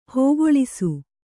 ♪ hōgoḷisu